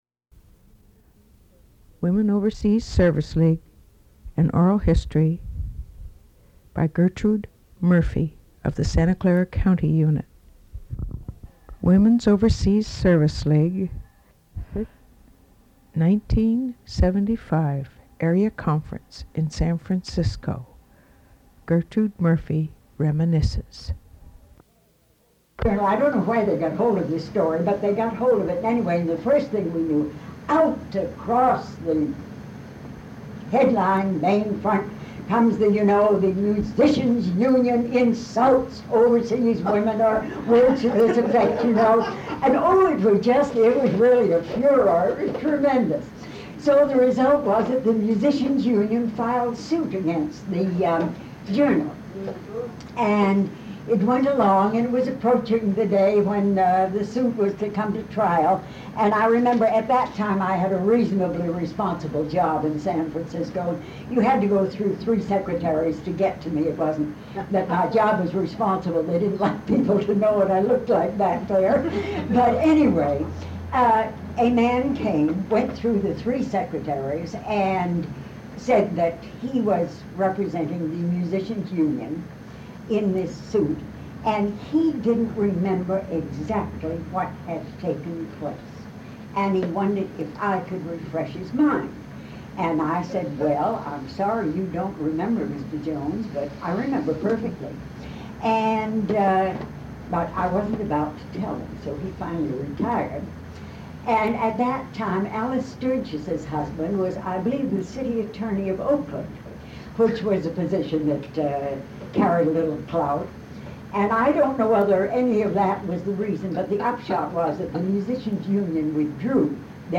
Recorded during the 1975 area conference held in San Francisco, CA.
Speeches (compositions)